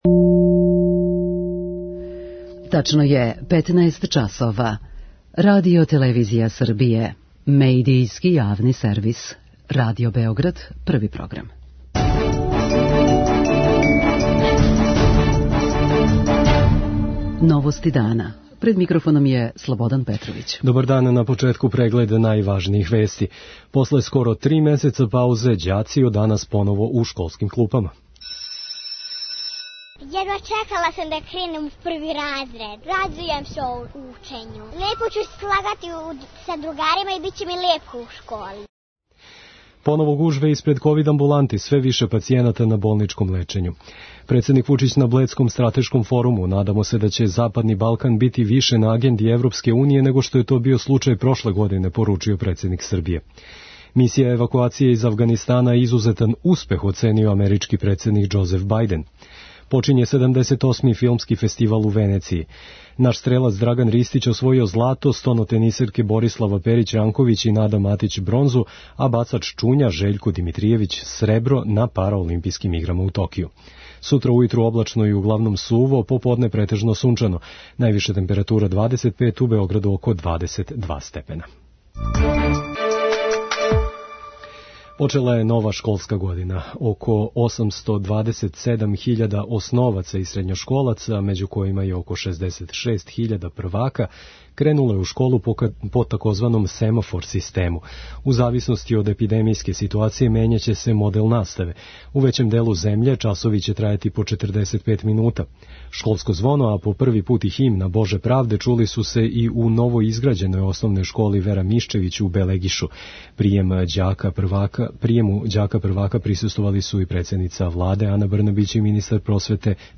Школска година је у школама широм Србије почела интонирањем државне химне „Боже правде". преузми : 6.54 MB Новости дана Autor: Радио Београд 1 “Новости дана”, централна информативна емисија Првог програма Радио Београда емитује се од јесени 1958. године.